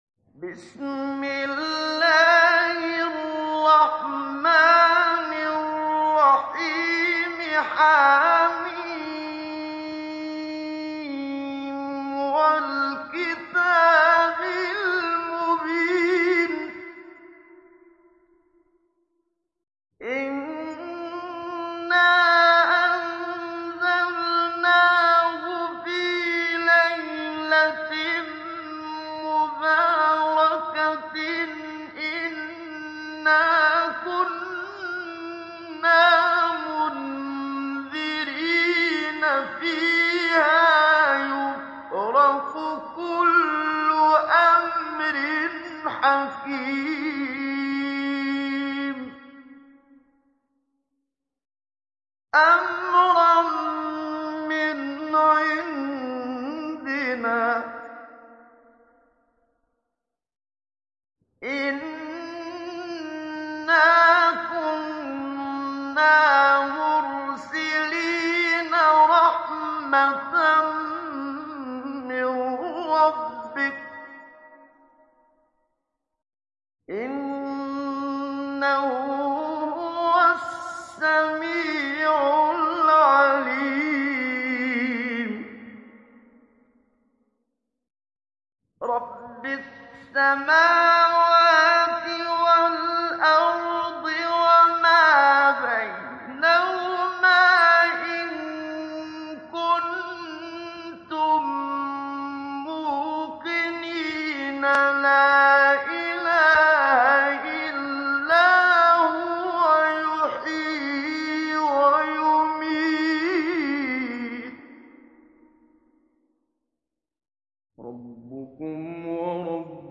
ডাউনলোড সূরা আদ-দুখান Muhammad Siddiq Minshawi Mujawwad